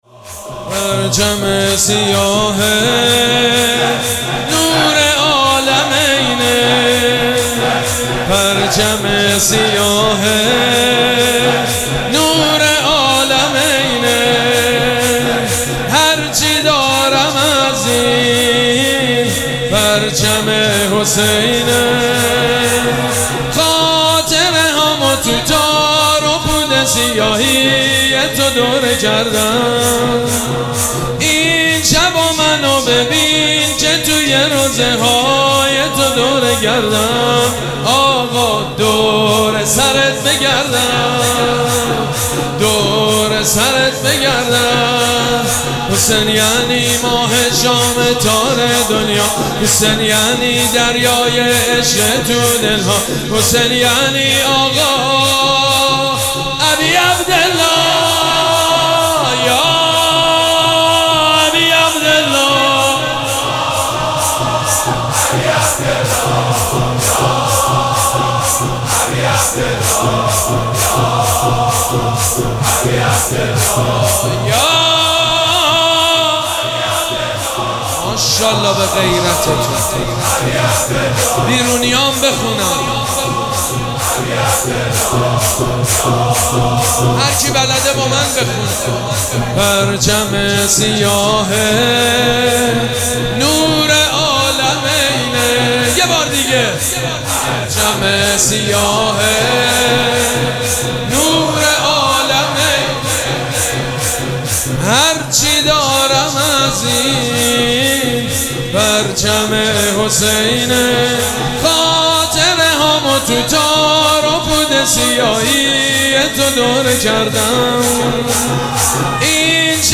مراسم عزاداری شب سوم محرم الحرام ۱۴۴۷
شور
مداح
حاج سید مجید بنی فاطمه